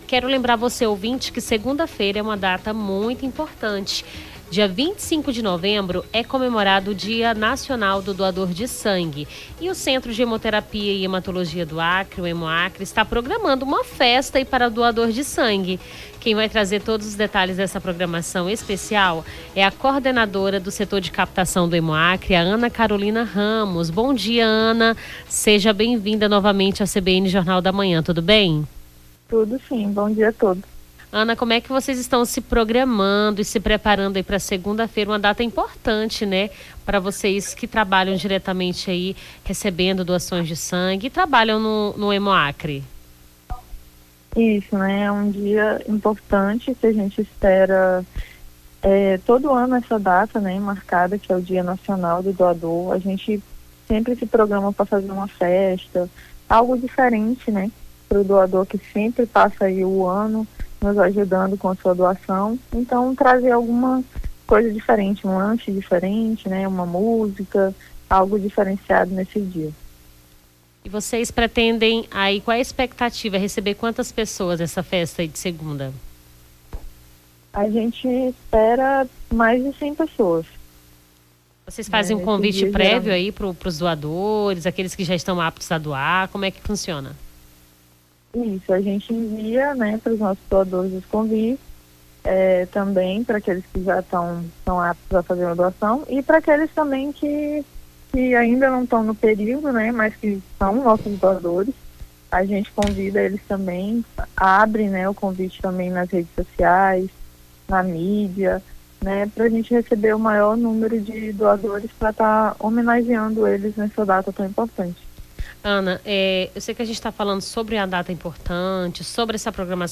Nome do Artista - CENSURA - ENTREVISTA DIA NACIONAL DO DOADOR DE SANGUE (21-11-24).mp3